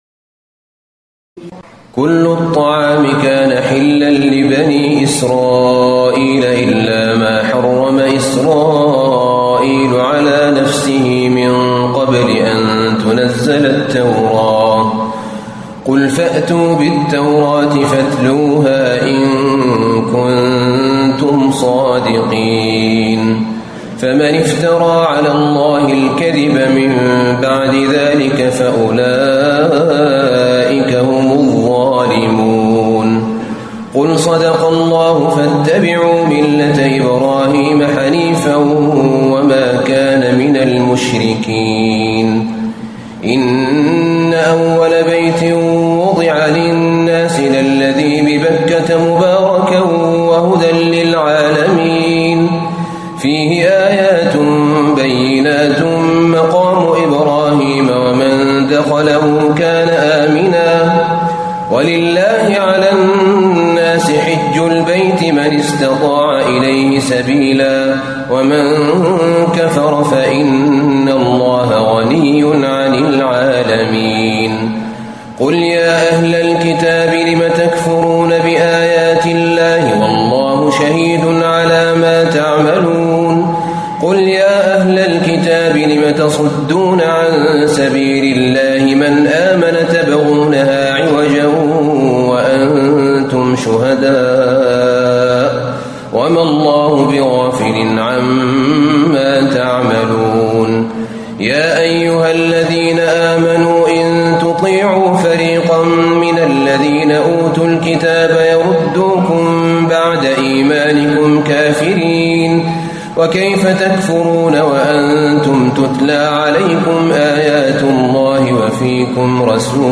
تراويح الليلة الرابعة رمضان 1437هـ من سورة آل عمران (93-168) Taraweeh 4 st night Ramadan 1437H from Surah Aal-i-Imraan > تراويح الحرم النبوي عام 1437 🕌 > التراويح - تلاوات الحرمين